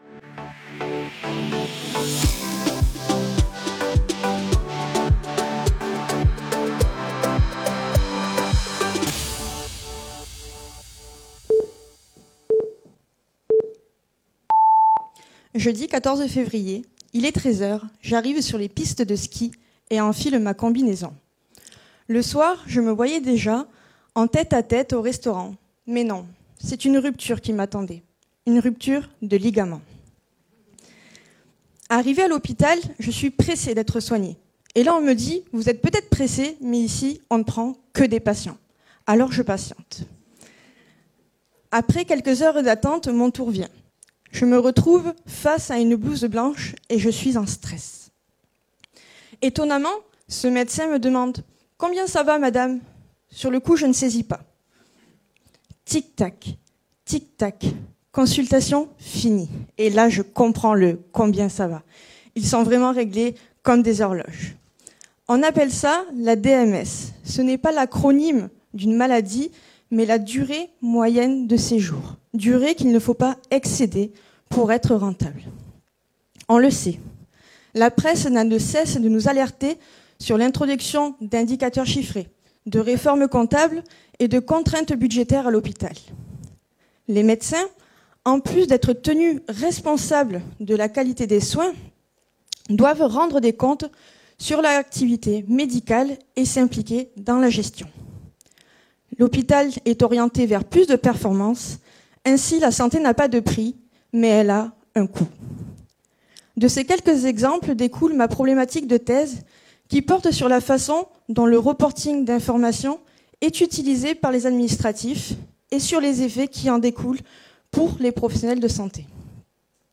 Pitch pour le Prix FNEGE de la Meilleure Thèse en 180 secondes 2018 / Prix ARAMOS L’objectif de cette thèse est d’analyser les effets et les rôles de l’accountability dans le secteur hospitalier public français sur la sphère médicale.